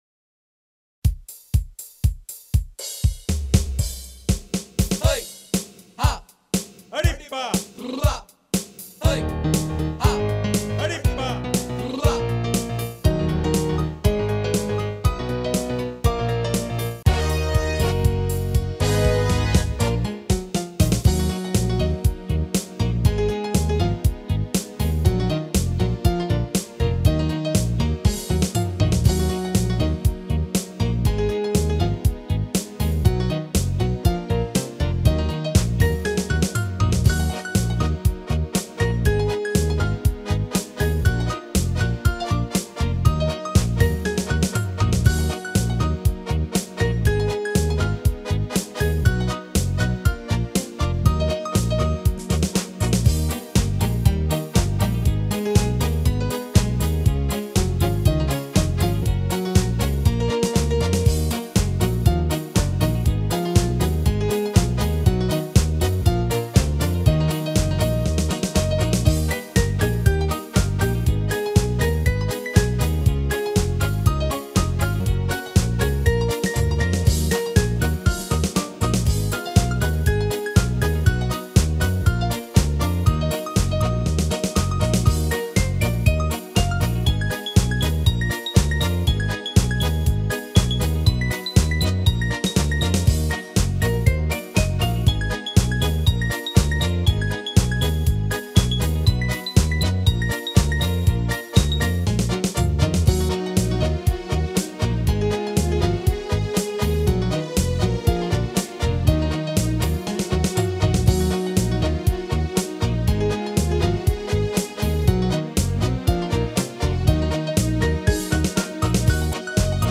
Симпатичная мелодия!
01.Arpeggpiano.mp3